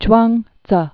(chwäng dzŭ) also Zhuang·zi (jwängdzŭ) c. 369-286 BC.